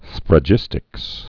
(sfrə-jĭstĭks)